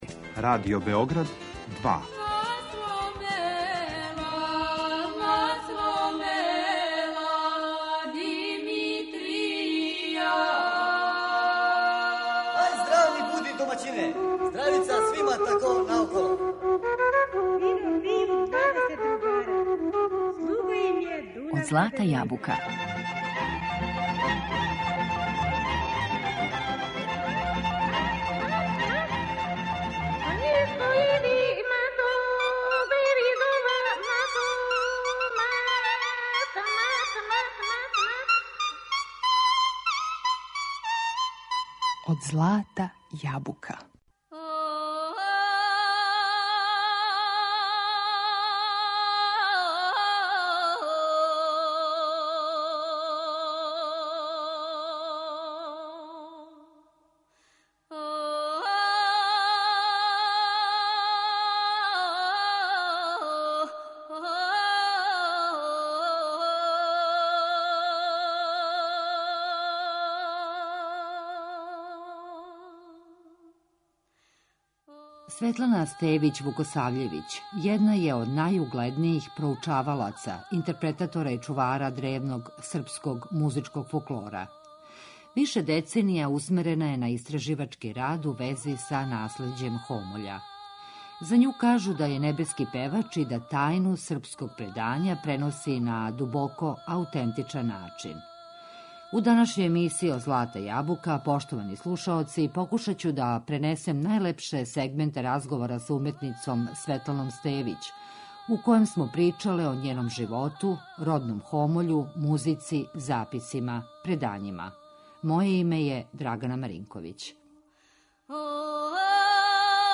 интерпретаторима и чуварима древног српског музичког фолклора.
За њу кажу да је „небески певач" и да тајну српског предања преноси на дубоко аутентичан начин.